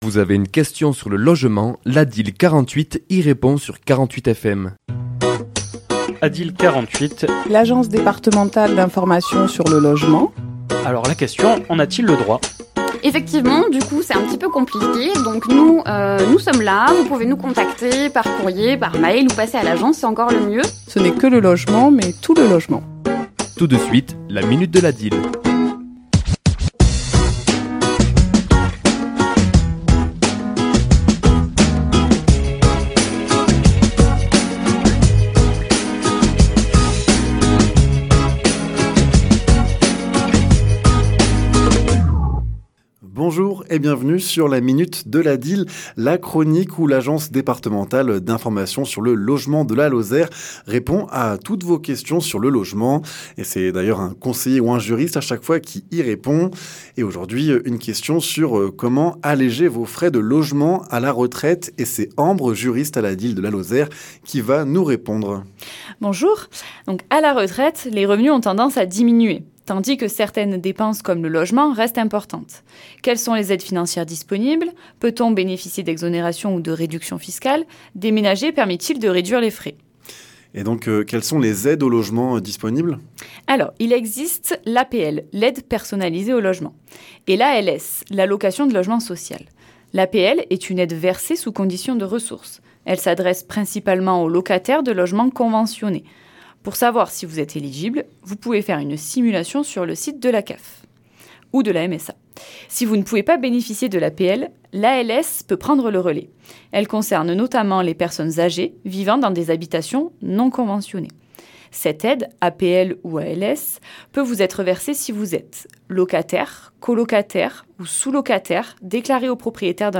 Chronique diffusée le mardi 9 décembre à 11h et 17h10